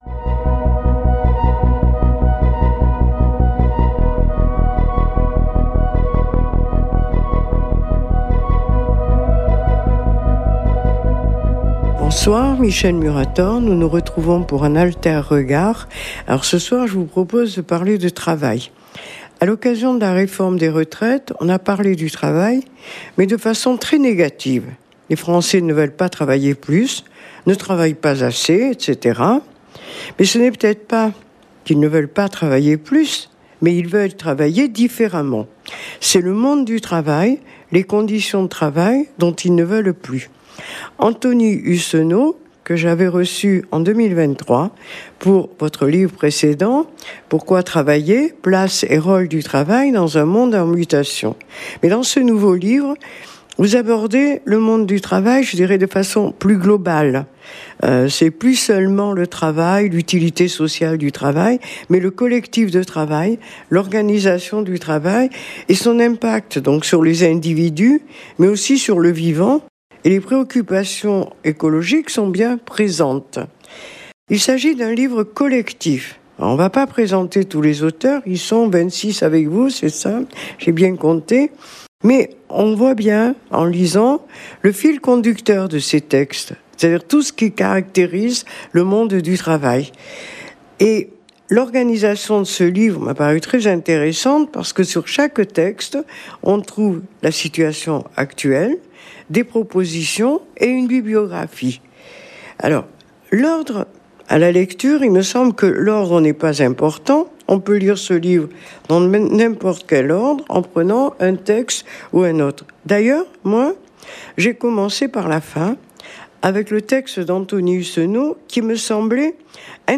Interview pour l’émission Alter-regard